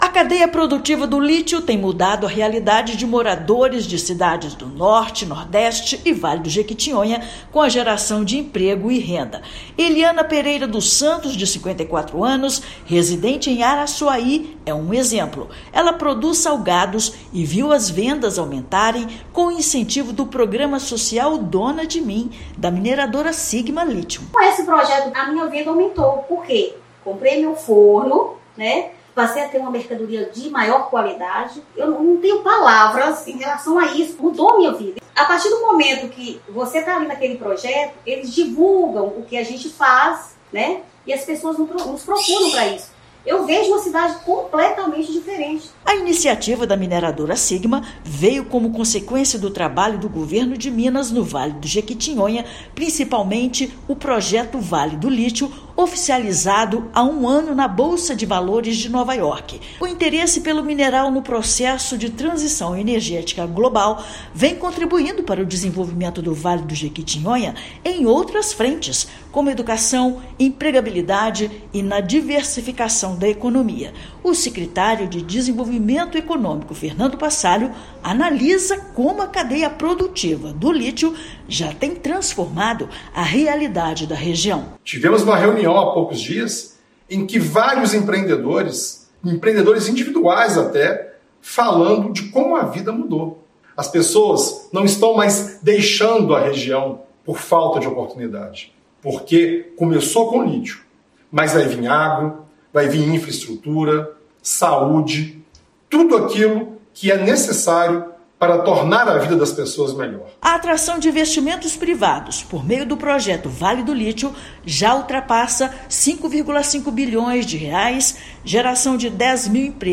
Chegada de novas indústrias, estímulo ao empreendedorismo de todos os portes e oportunidades de formação estão entre grandes estratégias de políticas públicas do Governo de Minas. Ouça matéria de rádio.